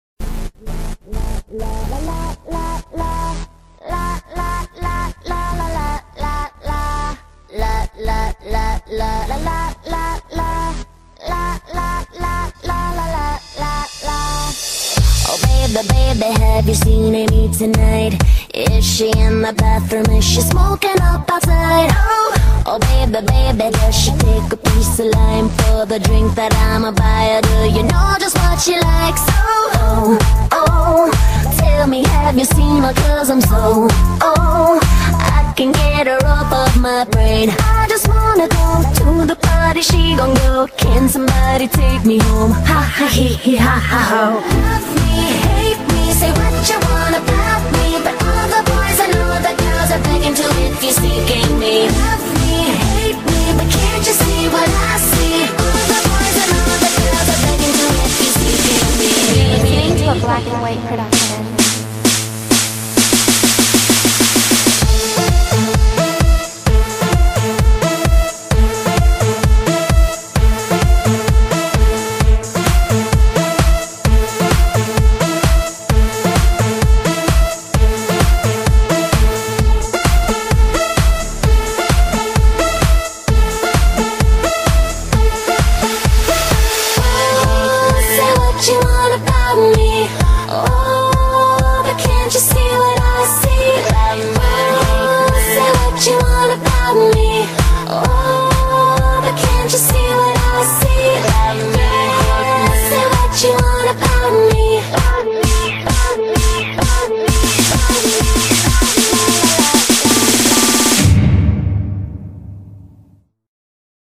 BPM130-130
Audio QualityMusic Cut